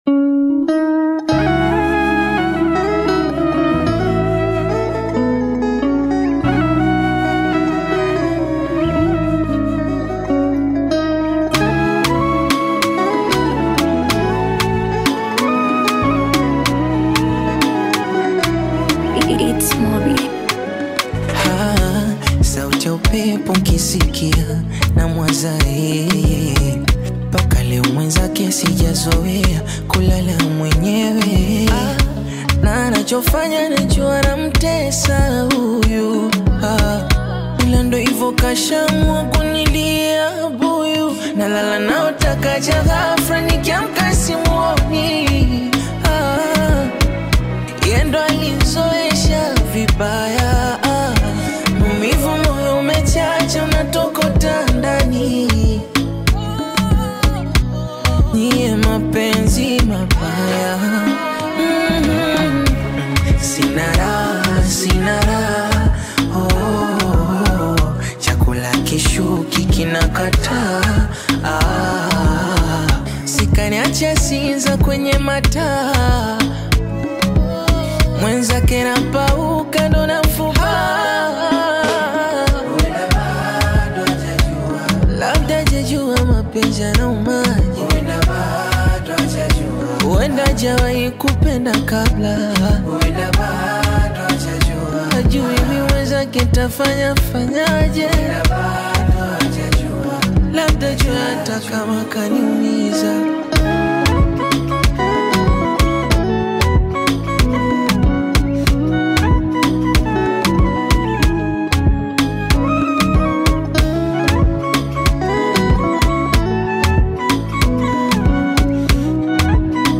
emotional Afro-Beat/Bongo Flava single
Genre: Bongo Flava